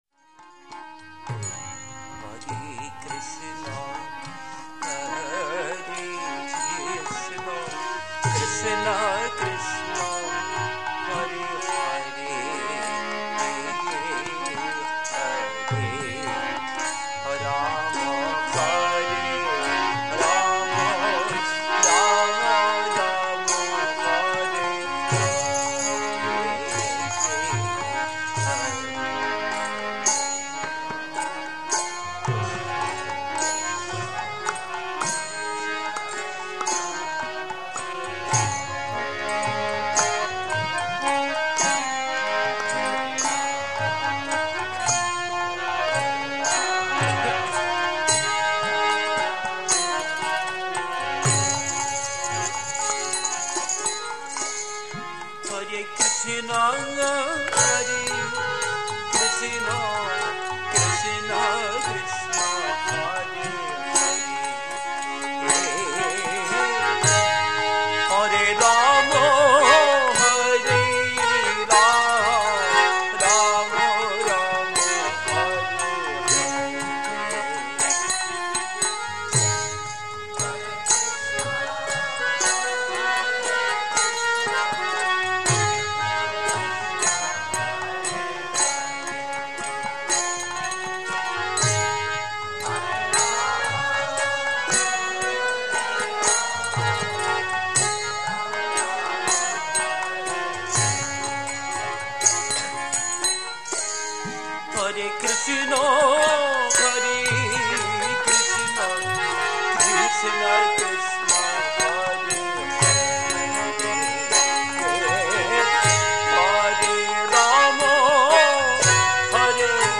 temple kirtans